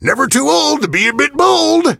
gale_kill_vo_02.ogg